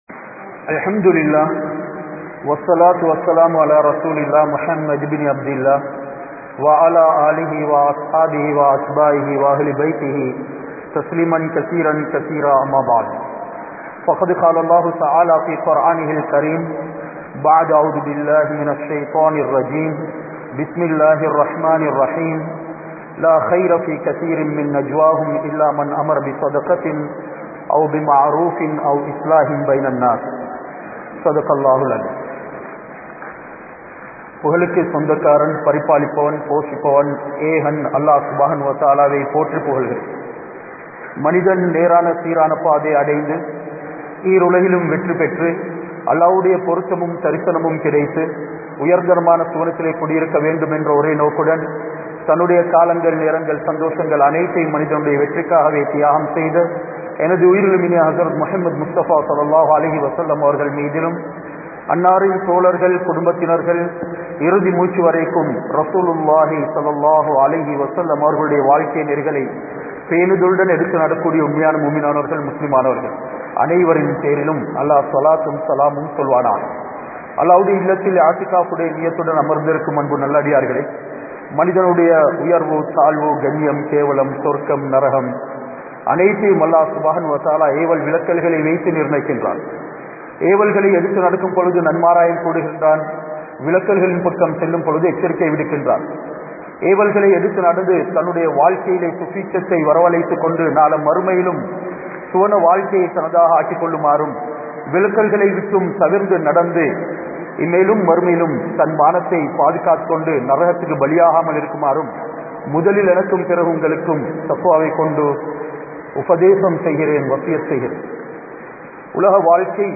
Samooha Nalan Peanungal (சமூக நலன் பேணுங்கள்) | Audio Bayans | All Ceylon Muslim Youth Community | Addalaichenai
Walampoda Grand Jumua Masjith